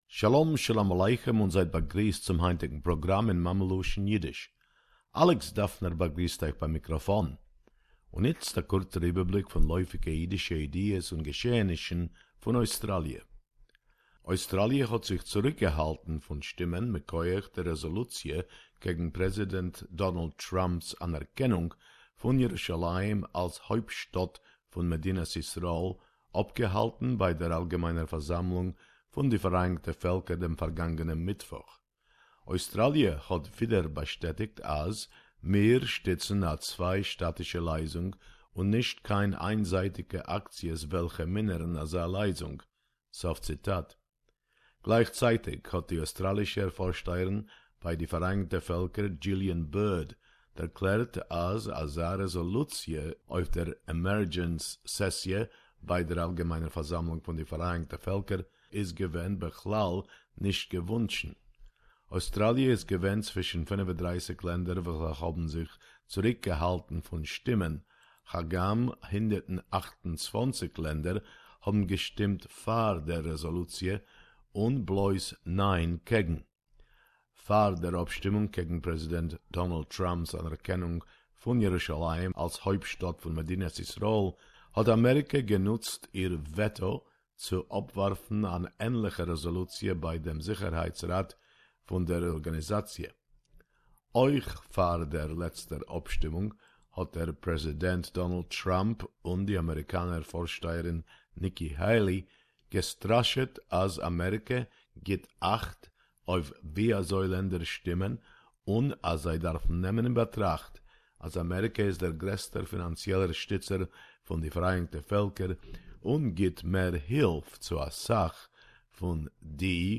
The latest news in Yiddish